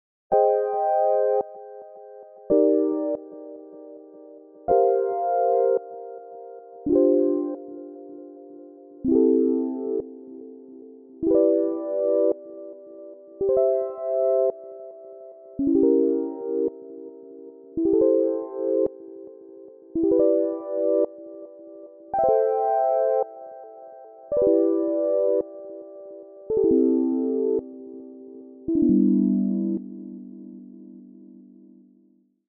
Nu wordt het tijd om onze twee noten (C en G) uit afbeelding 6 op verschillende manieren door Probabilty Arp te trekken in de hoop dat er iets leuks ontstaat. We variëren met Shape, Invert en Strum.
Dit is voor de goede orde dus het resultaat van alleen maar twee dezelfde muzieknoten die door Probability Arp gaan!